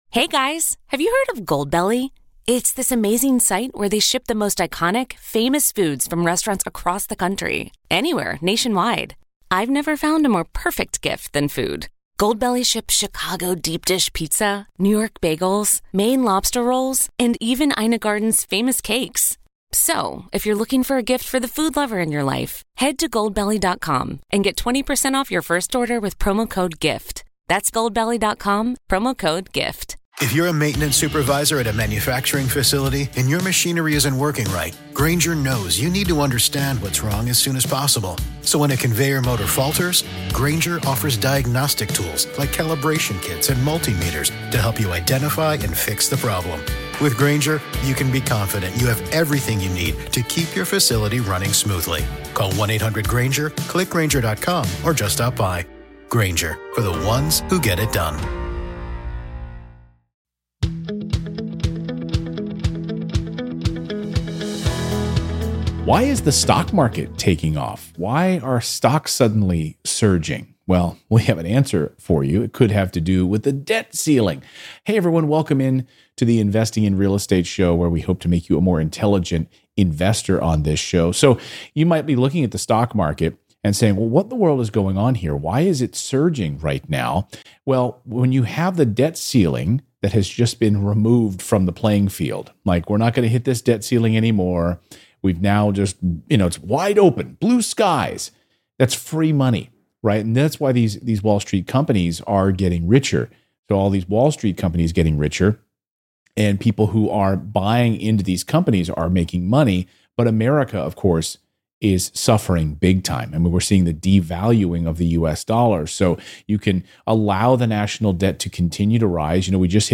Today's first caller wants to set up a self-directed IRA to buy real estate. What's the difference between Roth and traditional IRAs?